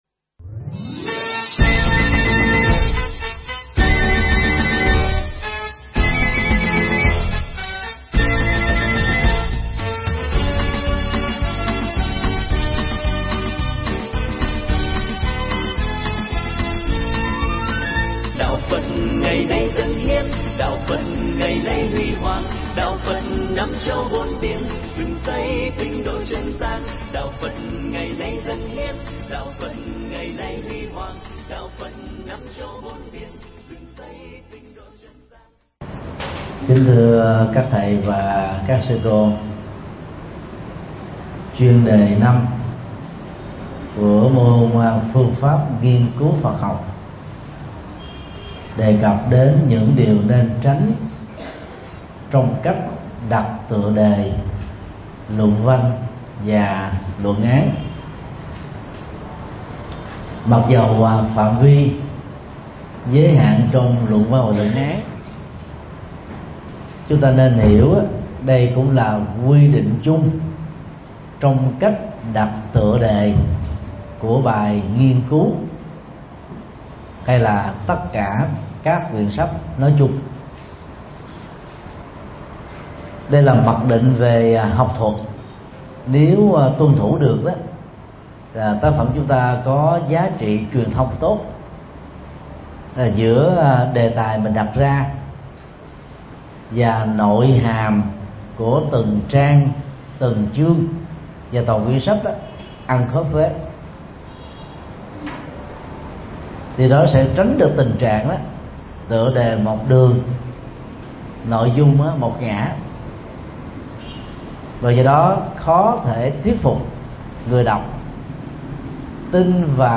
Mp3 Pháp Thoại PPNCPH5
giảng tại HVPGVN cơ sở 1 TPHCM